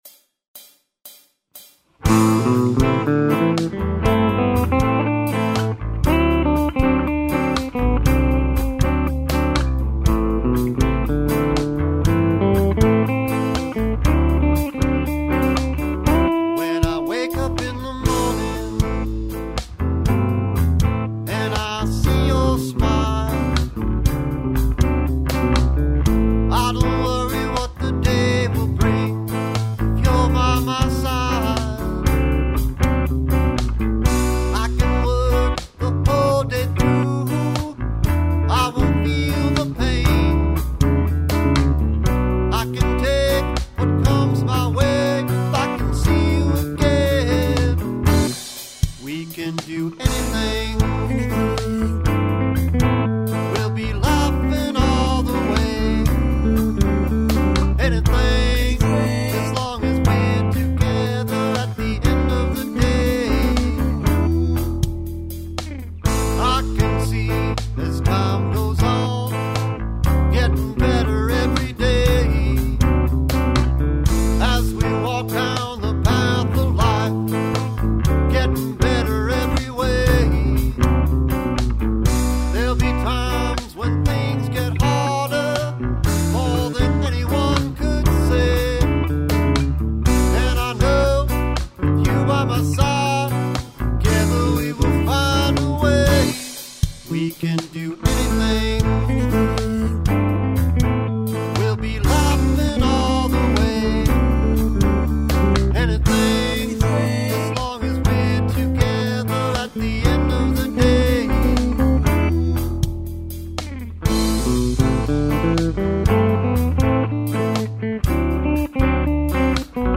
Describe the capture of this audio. interface Edirol UA101 using Sonar X1